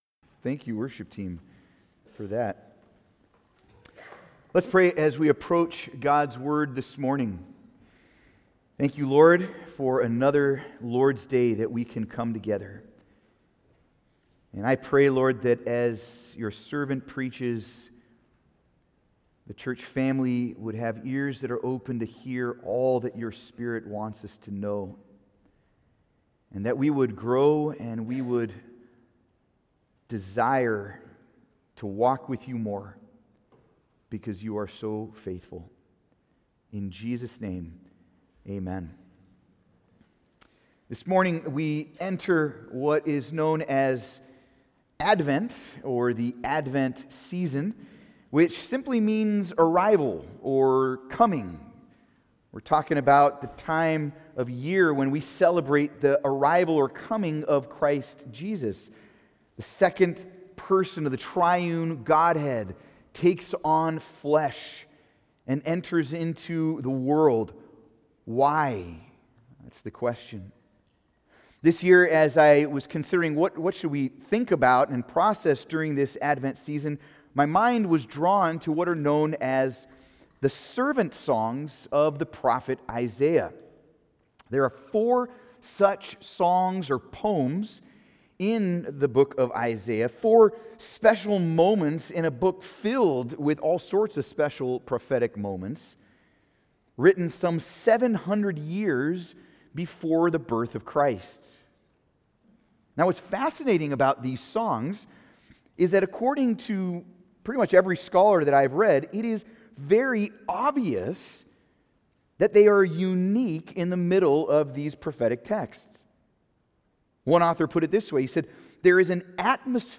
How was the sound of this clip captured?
Passage: Isaiah 42:1-9 Service Type: Sunday Service